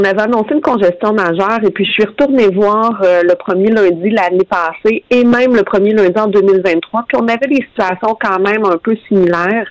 La porte-parole est revenu sur les dernières années à pareille date.